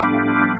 键盘 " 风琴07
44khz 16位立体声，无波块。
Tag: 键盘 器官 DB33